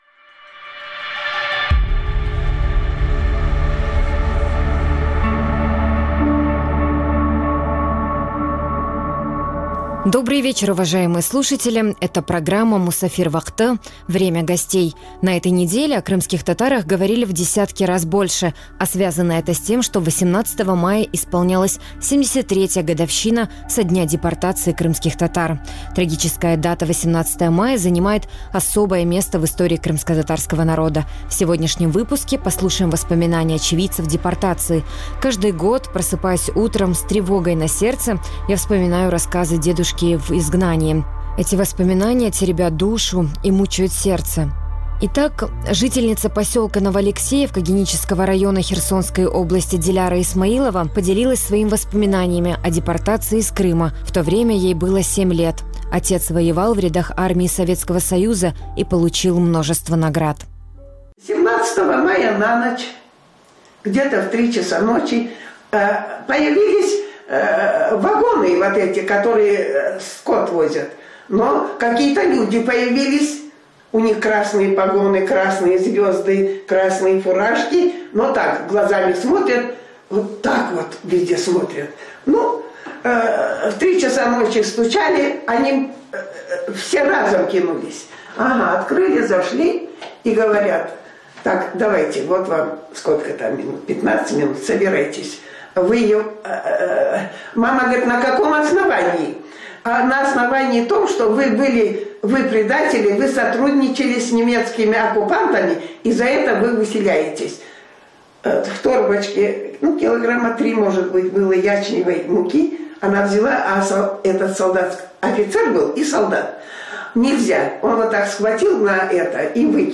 У сьогоднішньому випуску очевидці депортації 1944 року, згадують страшний день в історії кримськотатарського народу. Це 3 історії, прослухавши які, виникає безліч питань.
Програма звучить в ефірі Радіо Крим.Реалії. Час ефіру: 18:40 – 18:50.